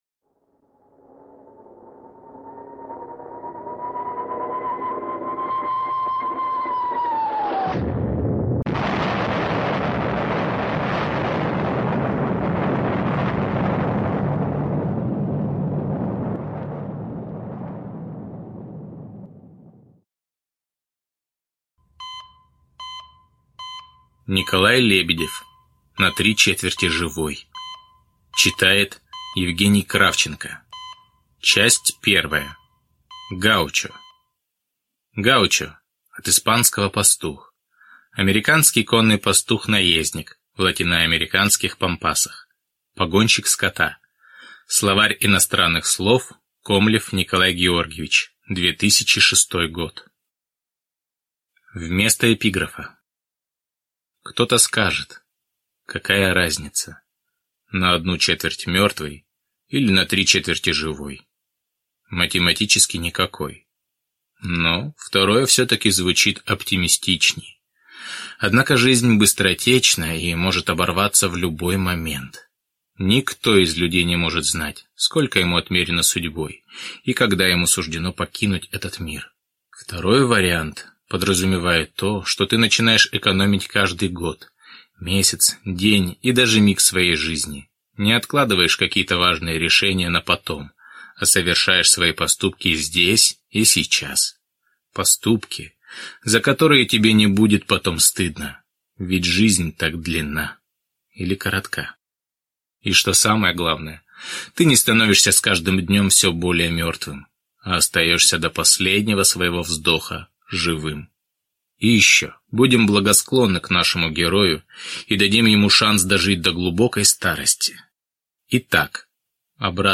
Аудиокнига На 3/4 живой | Библиотека аудиокниг